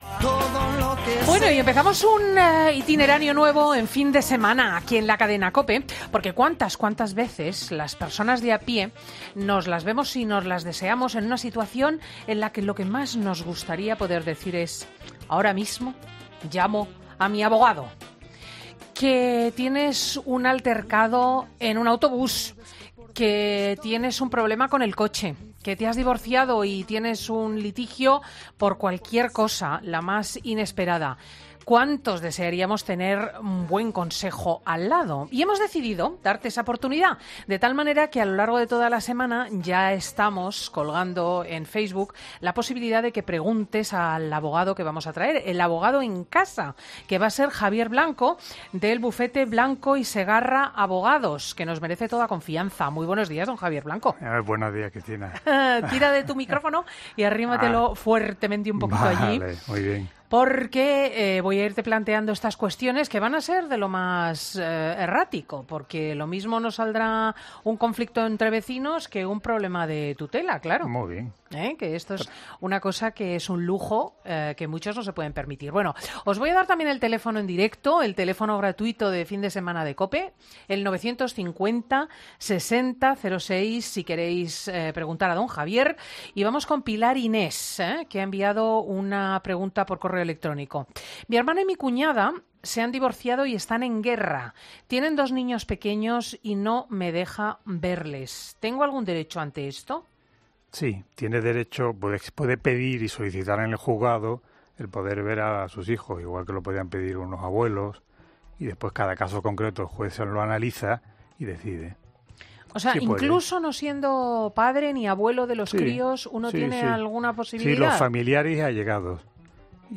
nos resuelve todas las dudas de los oyentes.